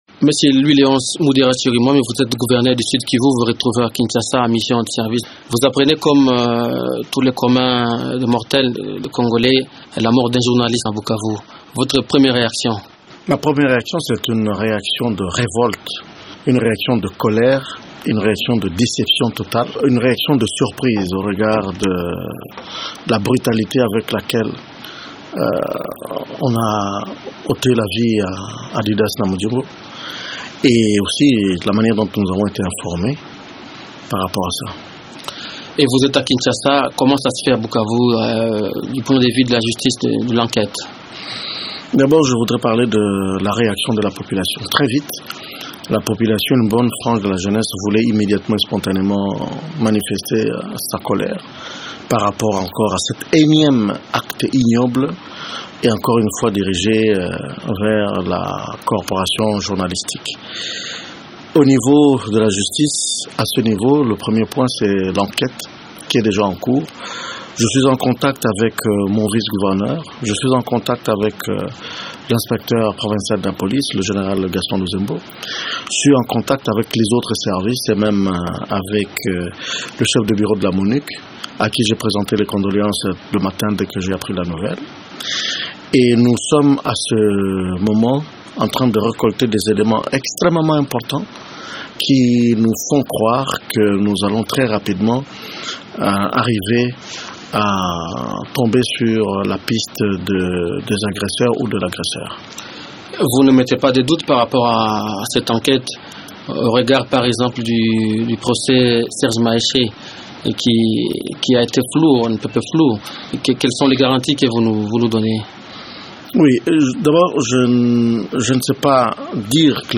Louis Léonce Mudherwa Chirimwami gouverneur du Sud Kivu s’entretient